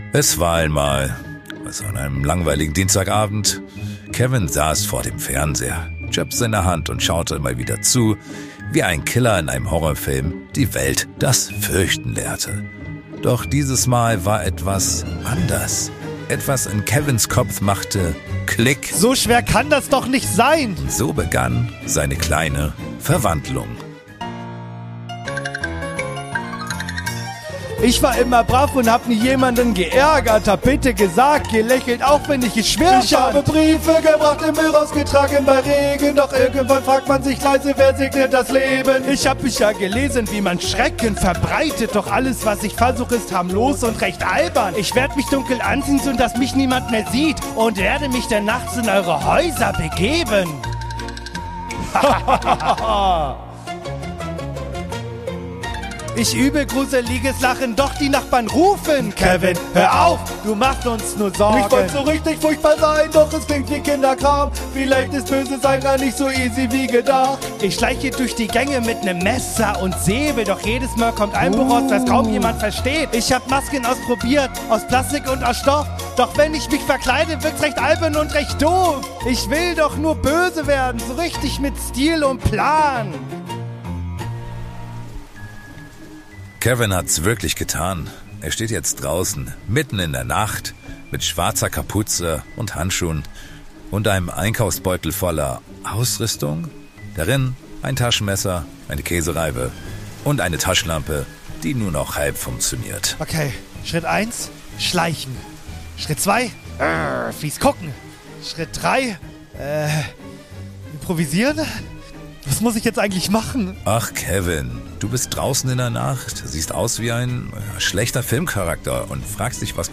Es ist mal wieder Halloween, also haben wir ganz tief in die Gruselkiste gegriffen und euch ein schauriges Musical für das Intro geschrieben!